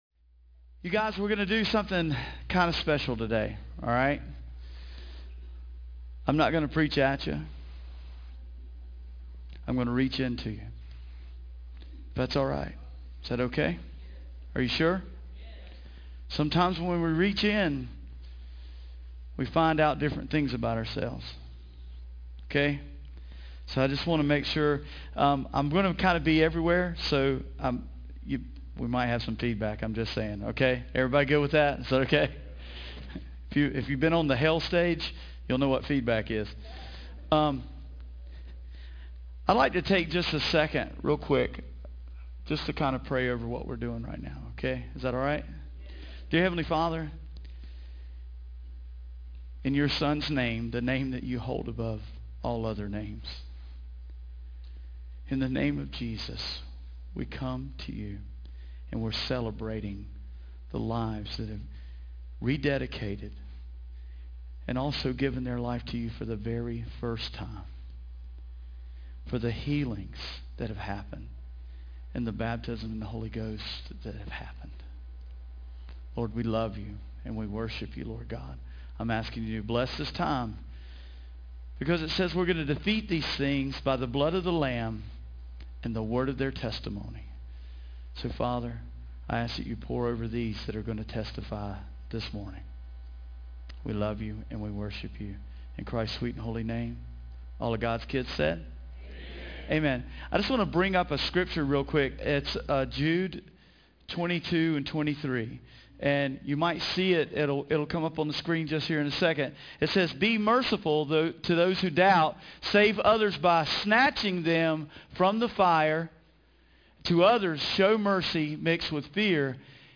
Helloween Celebration Service 2022